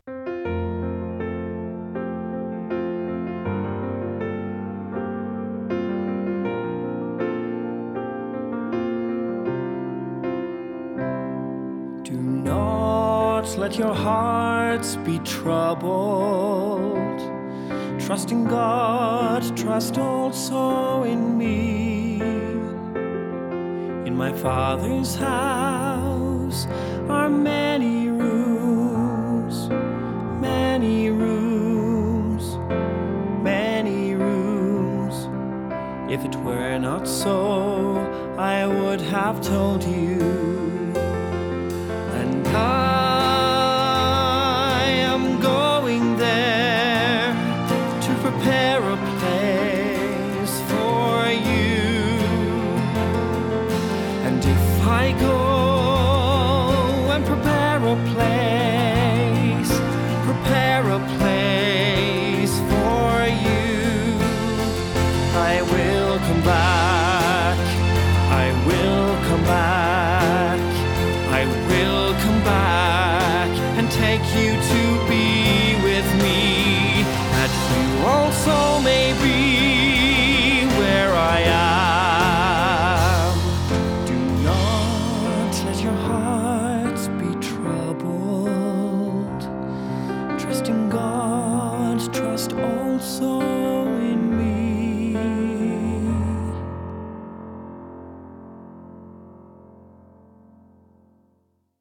“Original Cast Recording”